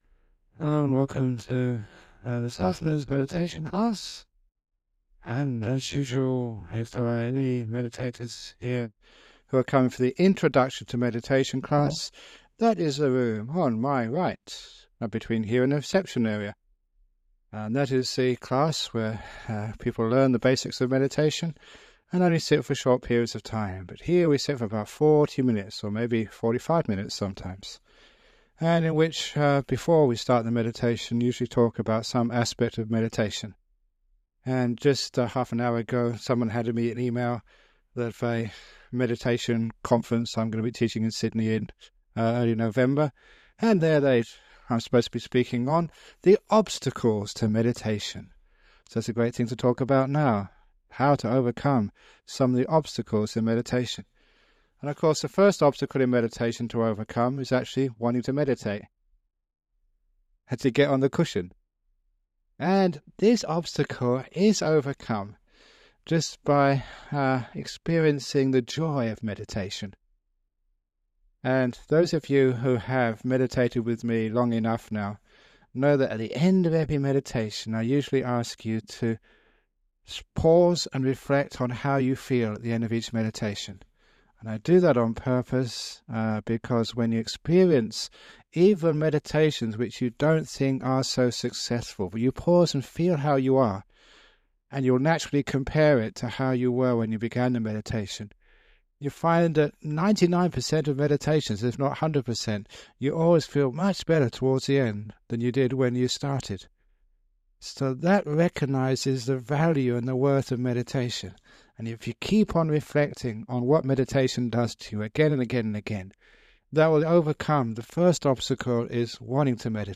It includes a talk about some aspect of meditation followed by a 45 minute guided meditation. This guided meditation has been remastered and published by the Everyday Dhamma Network , and will be of interest to people who have started meditation but are seeking guidance to take it deeper.